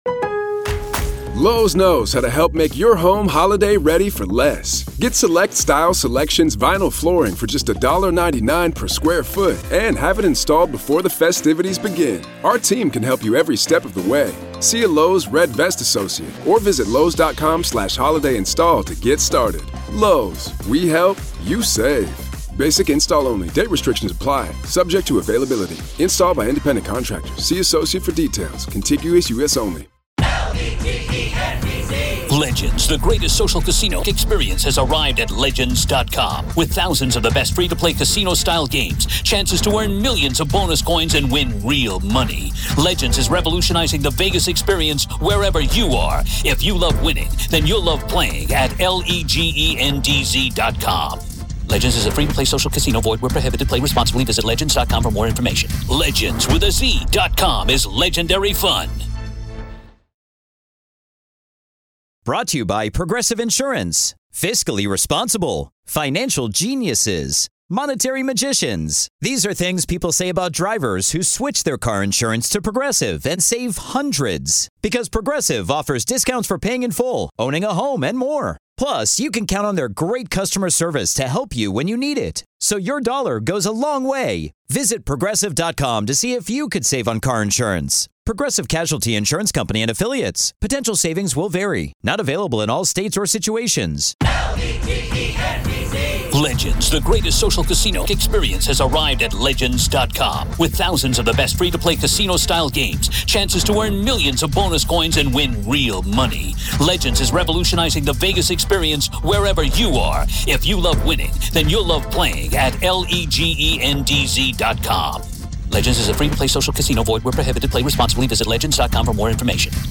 The team debates whether Lori Vallow and Alex Cox were the true hands behind the killings while Chad acted as the enabling bystander. They revisit the evidence: cell phone pings, asphyxiation findings from Tammy Daybell’s exhumation, and the suspicious burial of the children on Chad’s property.